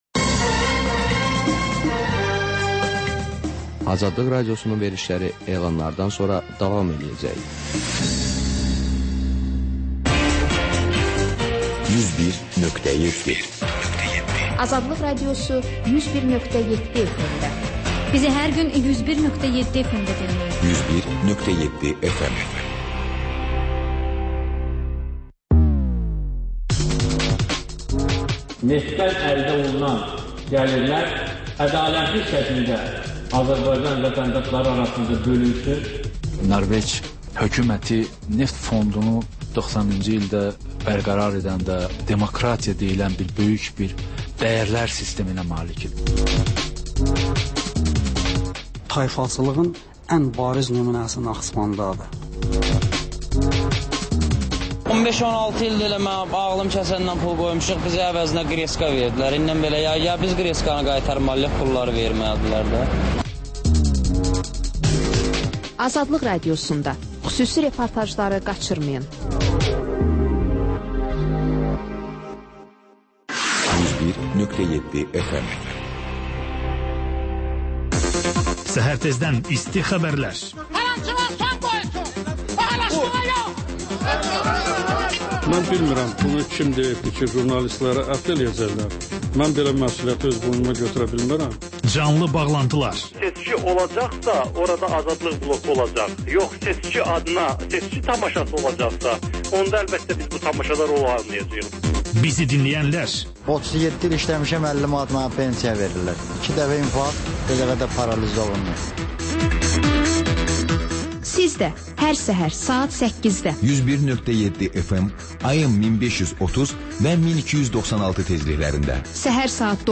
Xəbərlər, sonra CAN BAKI: Bakının ictimai və mədəni yaşamı, düşüncə və əyləncə həyatı… Həftə boyu efirə getmiş CAN BAKI radioşoularında ən maraqlı məqamlardan hazırlanmış xüsusi buraxılış